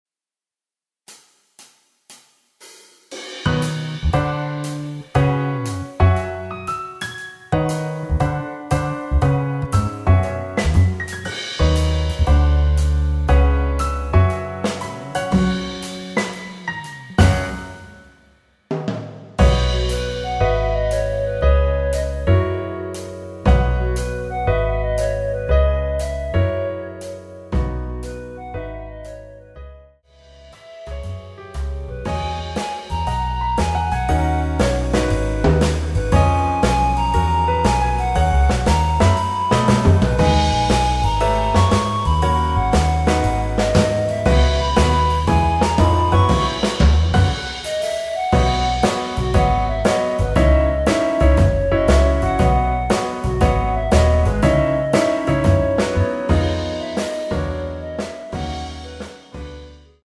◈ 곡명 옆 (-1)은 반음 내림, (+1)은 반음 올림 입니다.
노래방에서 노래를 부르실때 노래 부분에 가이드 멜로디가 따라 나와서
멜로디 MR이라고 합니다.
앞부분30초, 뒷부분30초씩 편집해서 올려 드리고 있습니다.
중간에 음이 끈어지고 다시 나오는 이유는